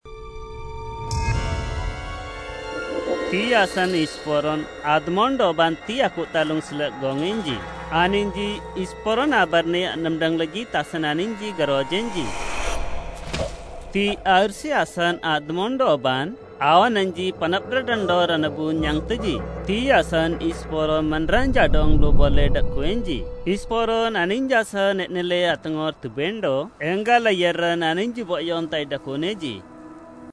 A dramatized presentation taken from the Book of Luke.
Audio from Video, Bible Stories, Discipleship, Drama